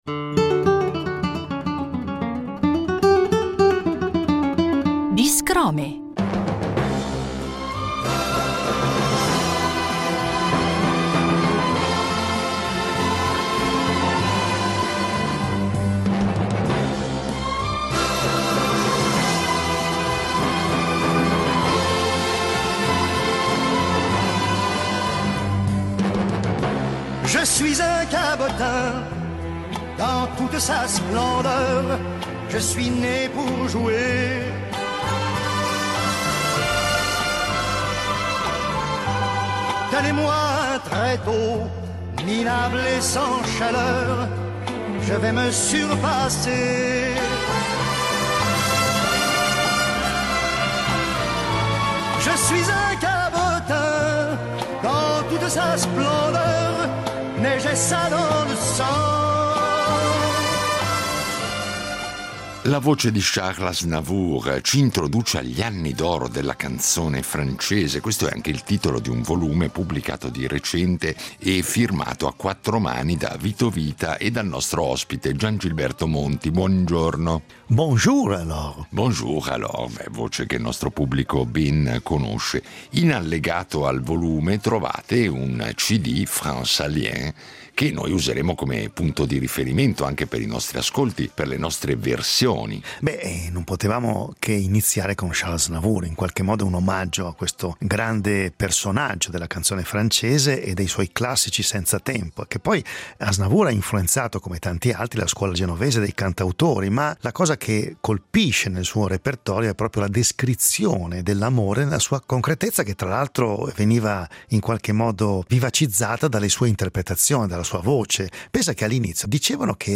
registrato dal vivo con una band di stampo jazzistico